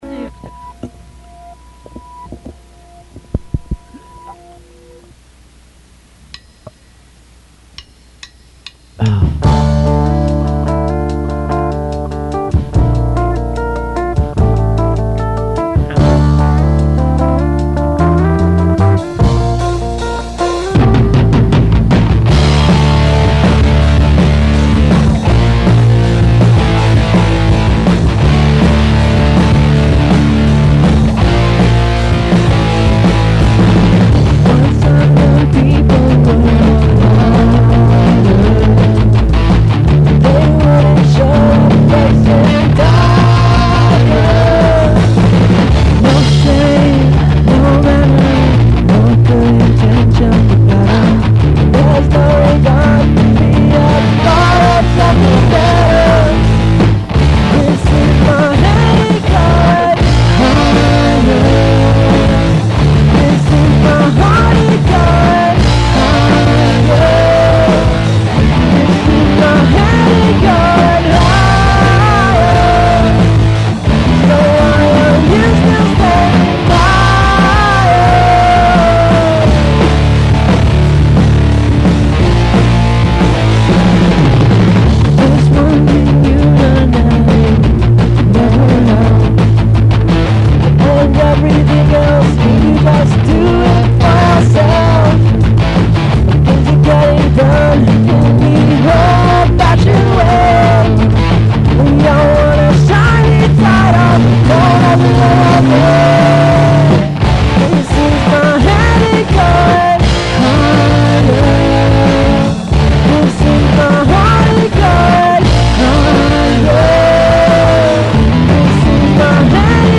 Below we will ad songs that we have recorded but sound like crap because of our cheap sound equipment.